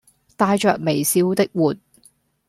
Голоса - Гонконгский 786